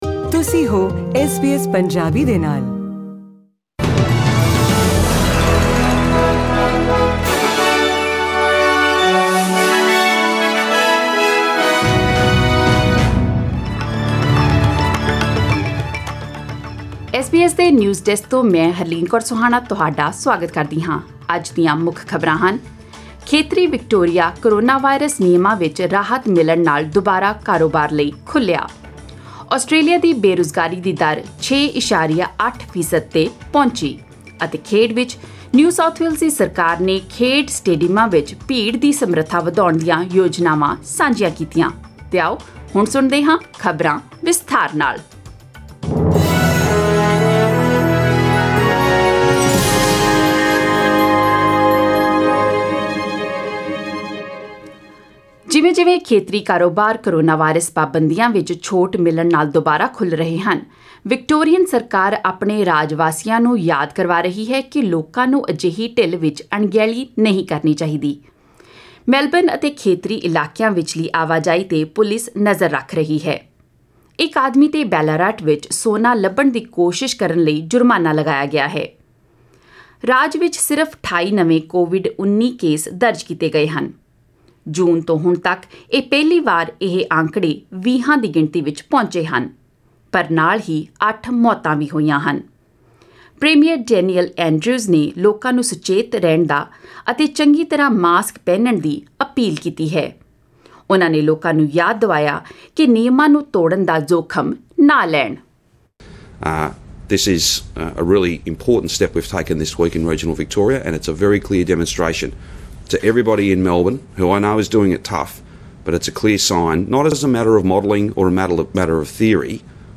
Australian News in Punjabi: 17 September 2020